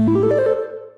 Techmino/media/effect/chiptune/spin_2.ogg at 89134d4f076855d852182c1bc1f6da5e53f075a4
spin_2.ogg